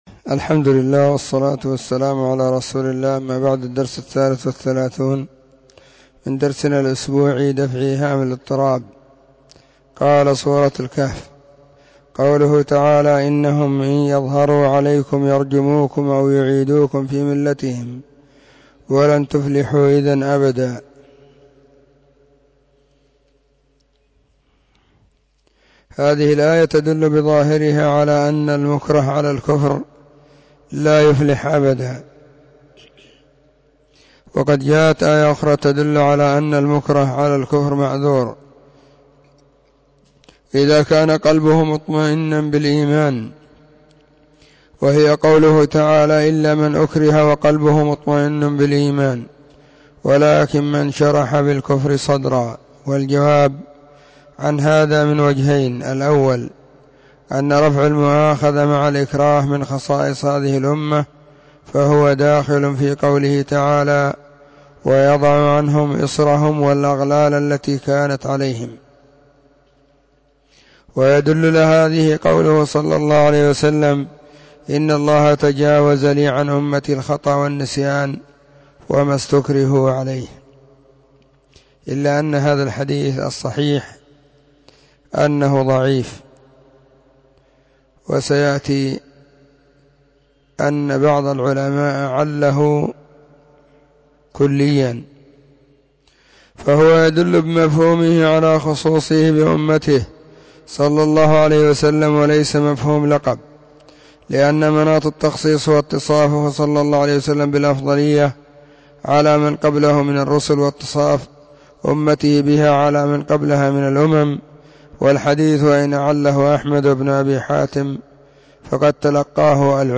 ⏱ [بعد صلاة الظهر في كل يوم الخميس]
📢 مسجد الصحابة – بالغيضة – المهرة، اليمن حرسها الله.